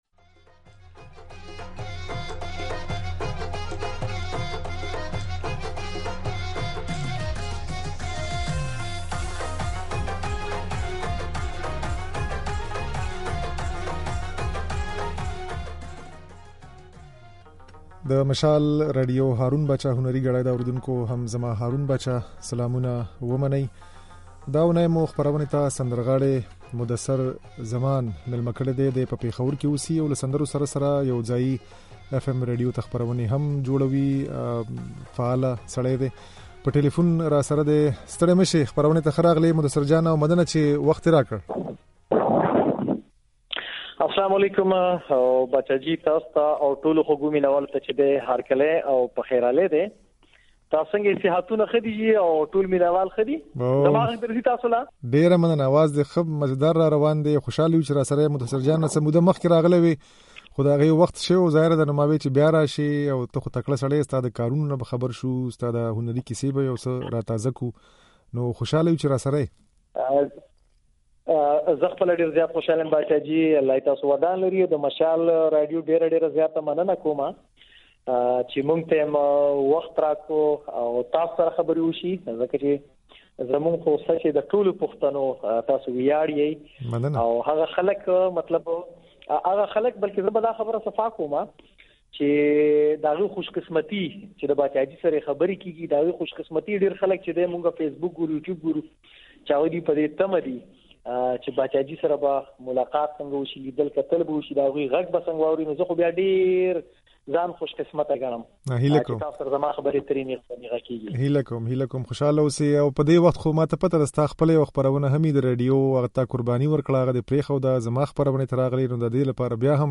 ځينې سندرې يې د غږ په ځای کې اورېدای شئ